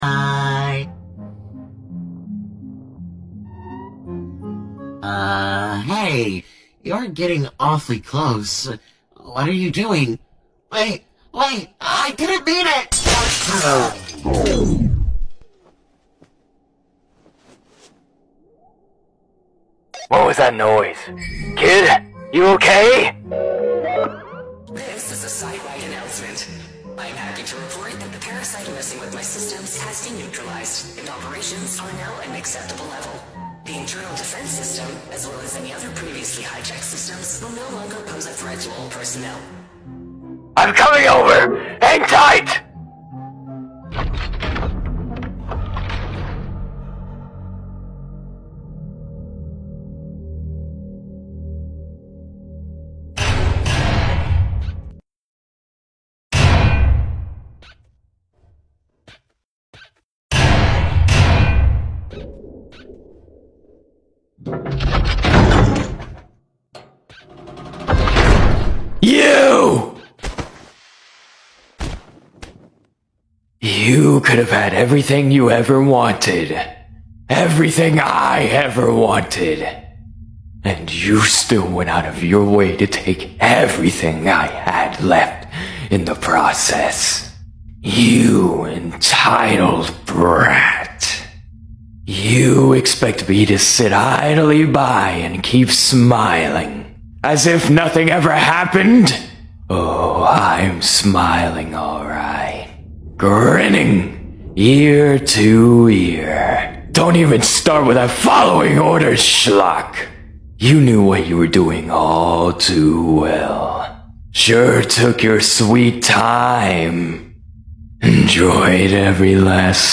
My voiceover as Sebastian Solace sound effects free download